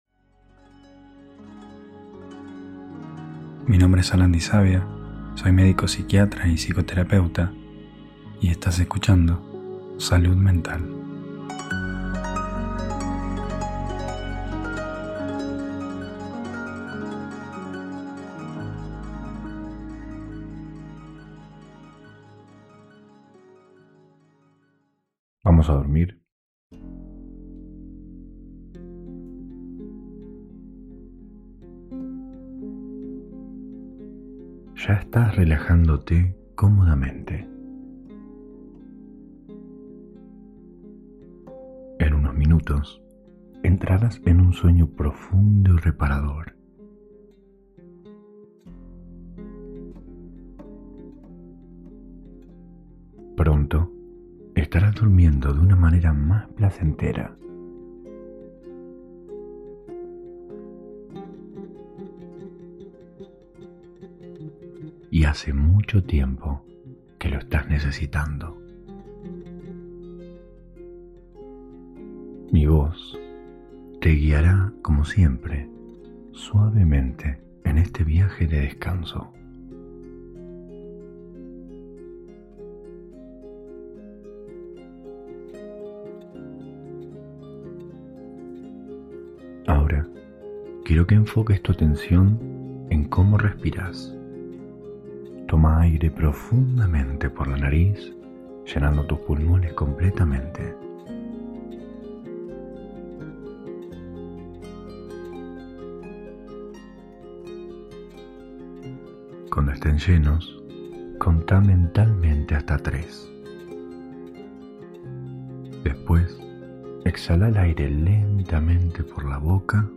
Hipnosis guiada para dormir.